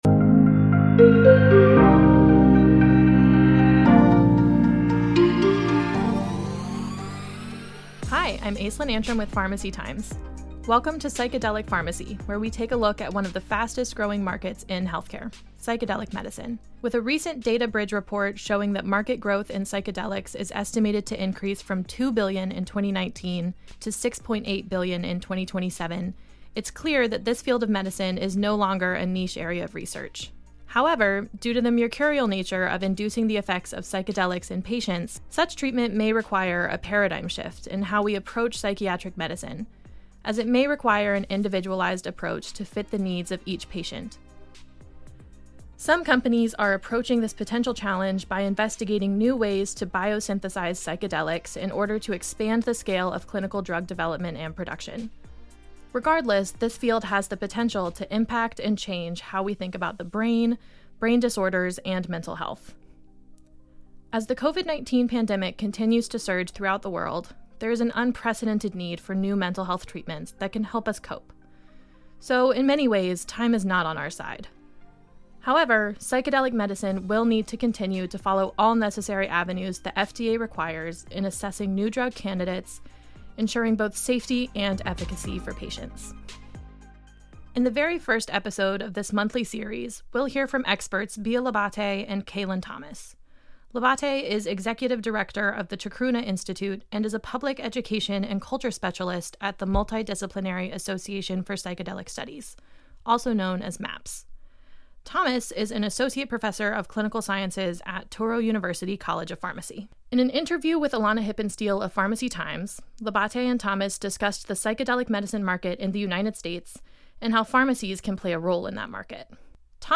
Each episode will explore the growing body of research about psychedelic medicine, with interviews from researchers, clinicians, and other experts.